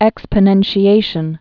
(ĕkspə-nĕnshē-āshən)